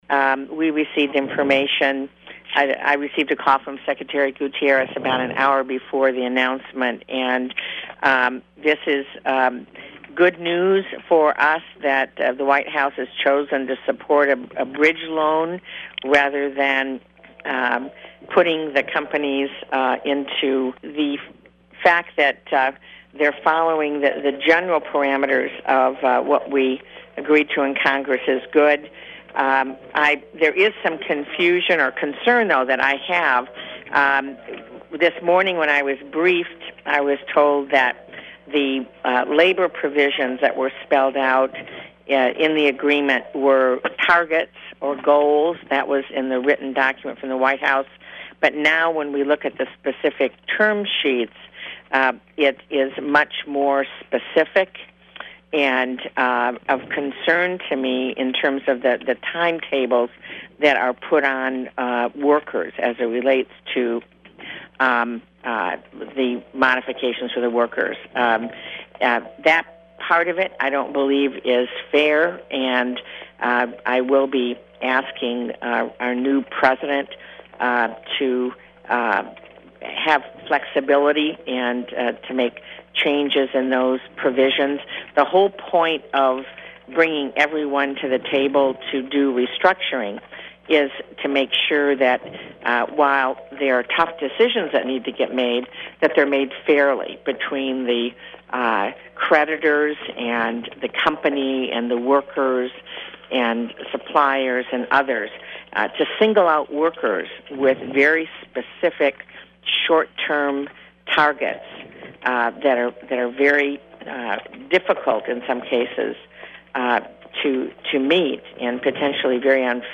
US Senator Debbie Stabenow (D-MI) Reaction to the Federal Car Industry Loan